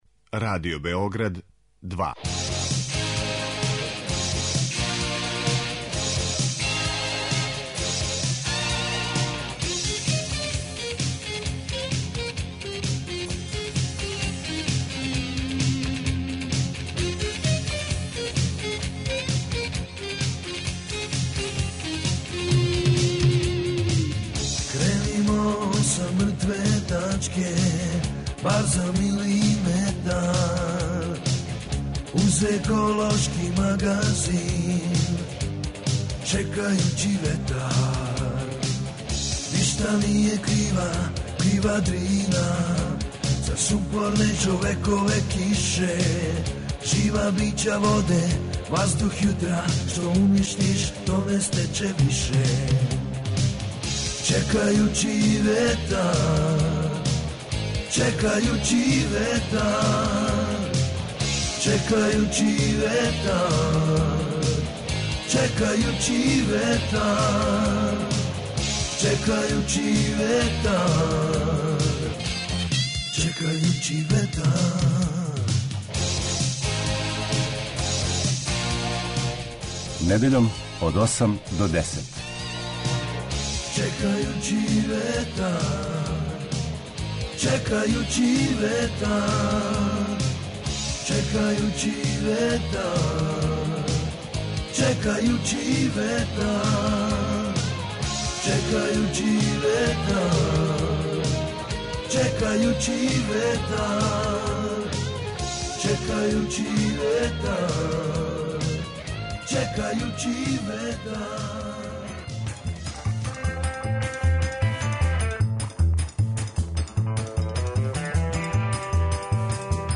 Еколошки магазин
Чућете како је било на додели, ко су добитници и како су говорили.